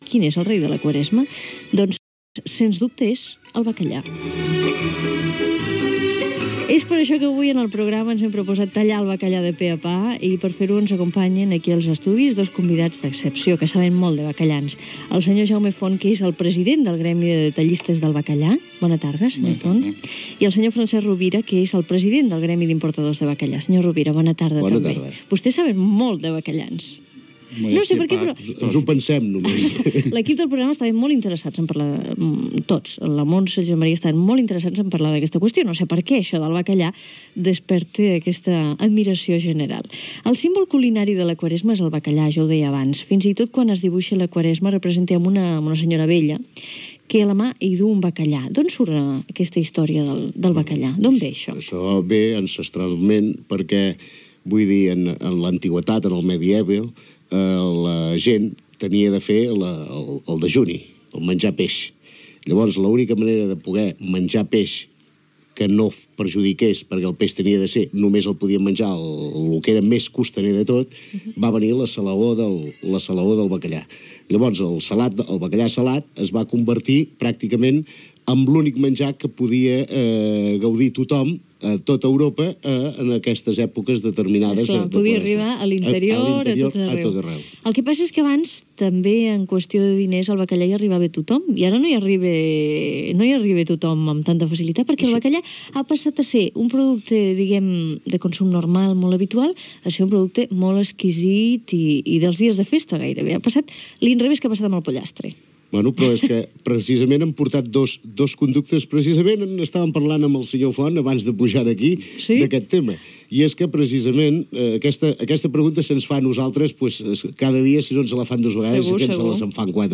Entrevista
Banda FM